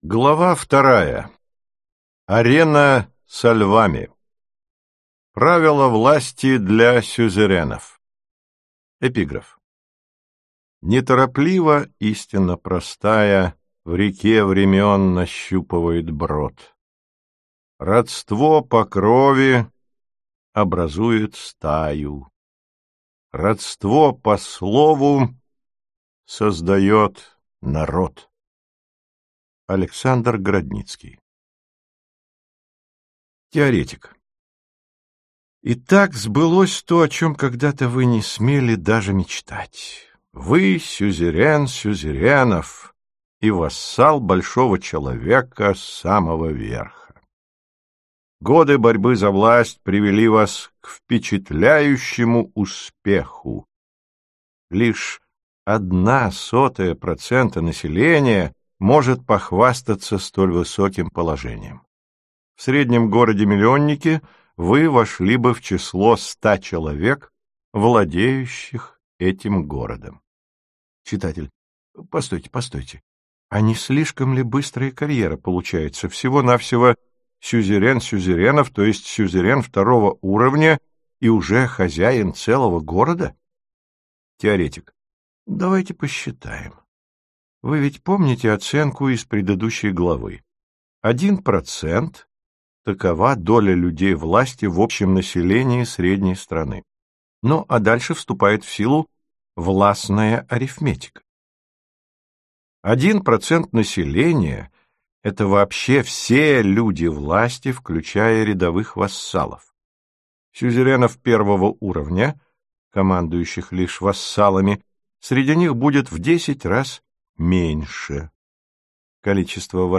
Аудиокнига Лестница в небо. Диалоги о власти, карьере и мировой элите. Часть 2 | Библиотека аудиокниг